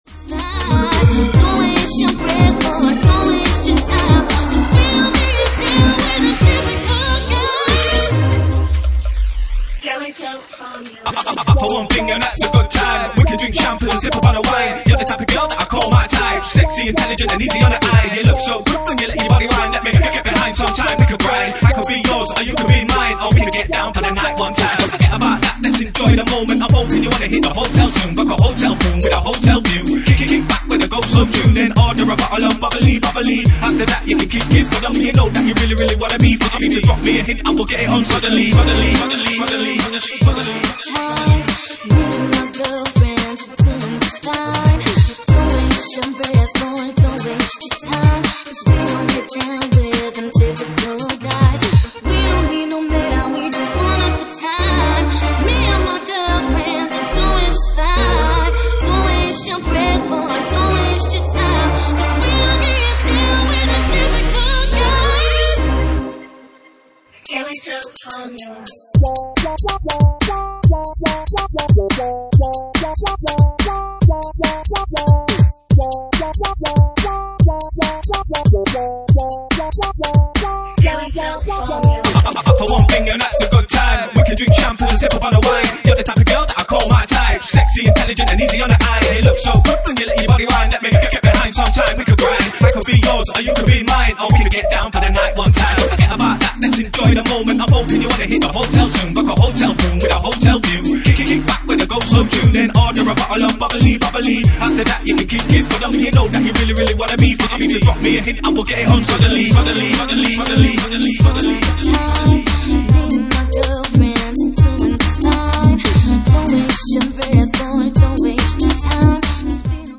Genre:Bassline House
Bassline House at 36 bpm